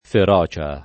fera] s. f.; pl. ‑cie — regolare l’-o- aperto in una parola di formaz. dòtta (dal lat. ferocia) e d’uso non molto popolare — tendenza recente in Tosc., soprattutto nella Tosc. di ponente, presso forse una metà dei parlanti, a chiudere la vocale per analogia con quella di feroce — cfr. atroce